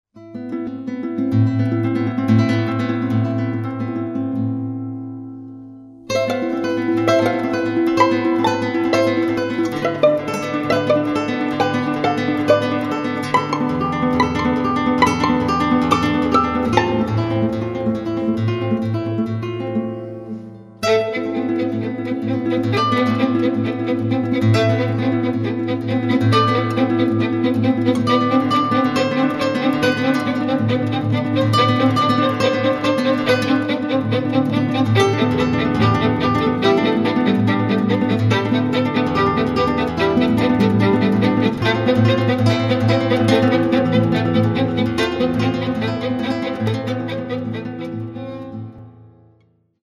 12 brani per chitarra e violino